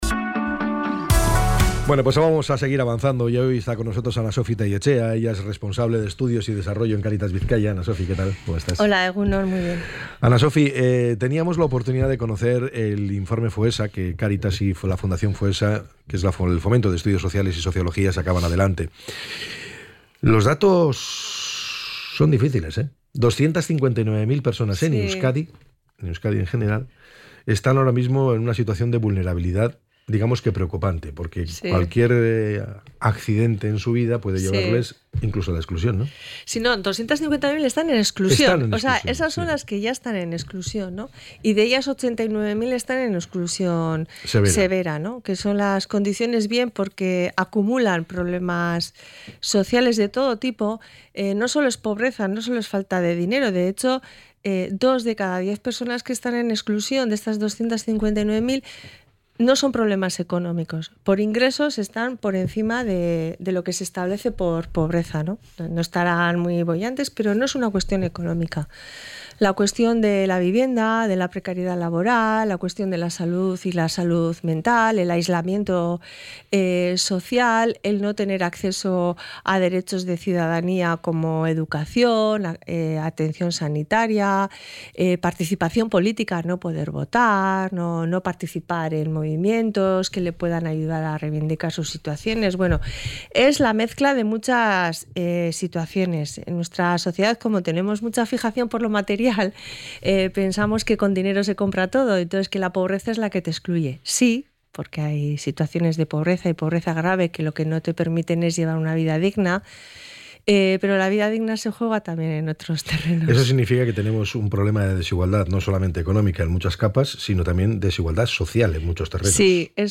ENTREV.-CARITAS.mp3